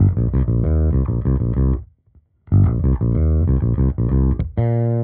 Index of /musicradar/dusty-funk-samples/Bass/95bpm
DF_JaBass_95-C.wav